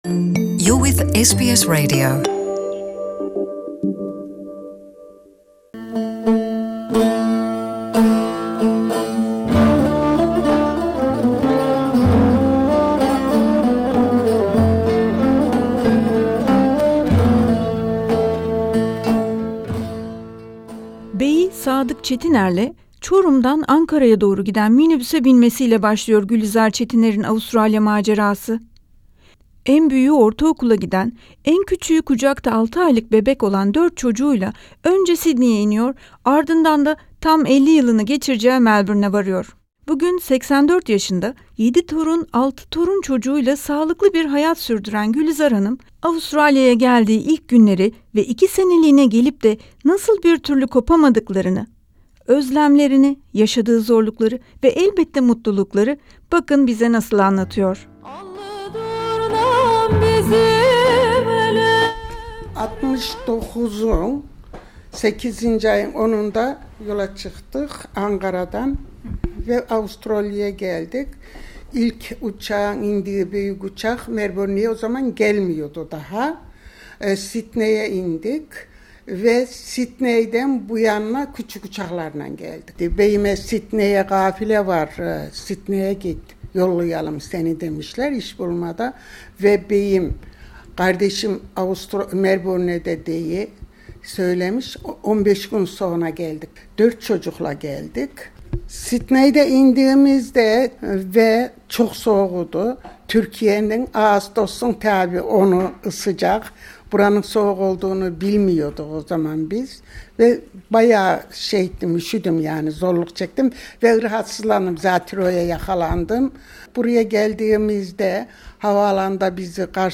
Avustralya-Türk toplumunun Türkiye'den gelişinin 50'nci, Kıbrıs'tan gelişinin 70'nci yılı kutlamaları çerçevesinde Avustralya'ya ilk gelen toplum bireylerimizle söyleşilerimiz sürüyor.